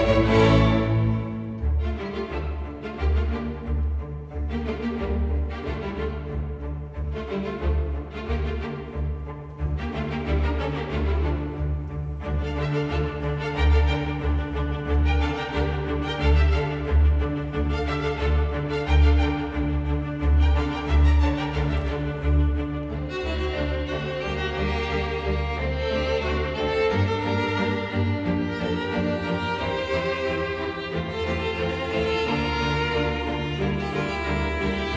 Classical
Жанр: Классика